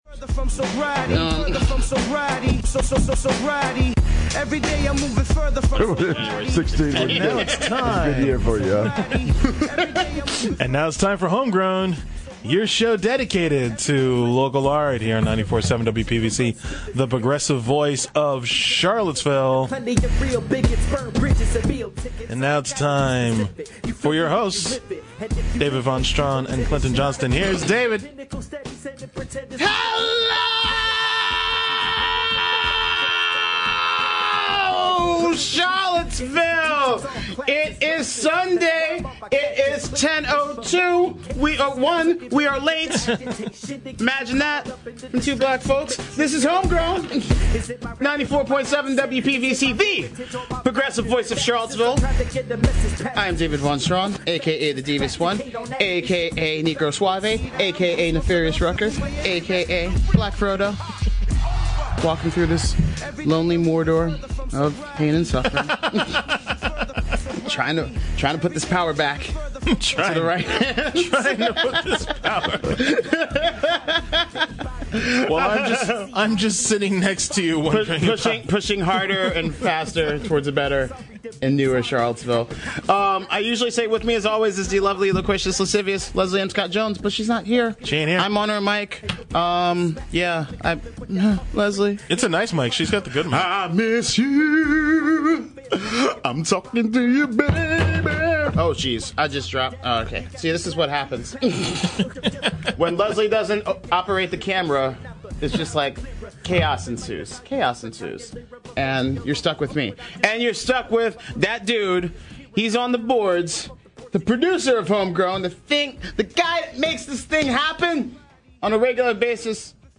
It’s two great conversations with two great guests on Home Grown: Your Show about Local Art .